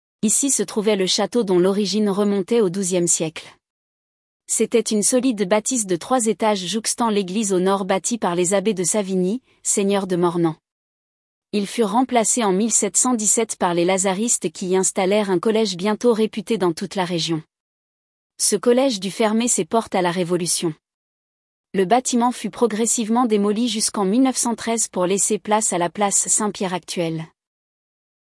audio guide de la Tour du Vingtain